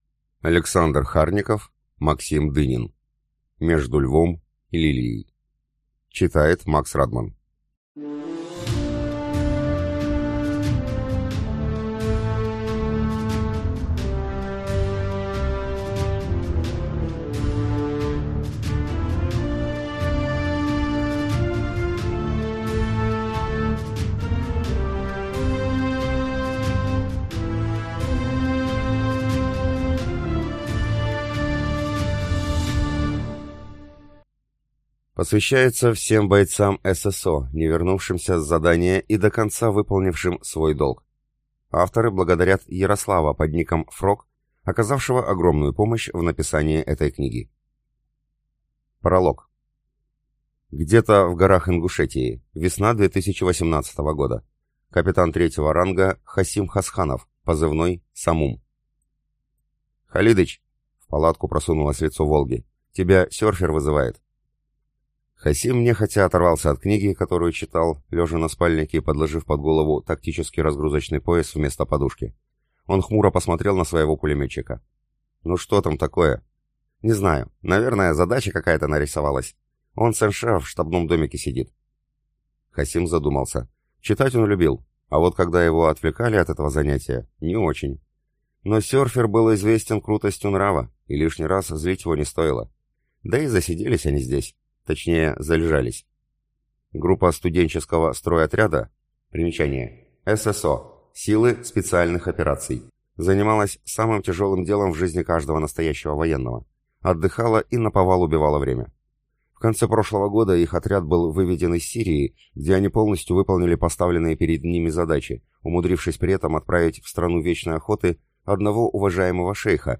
Аудиокнига Между львом и лилией | Библиотека аудиокниг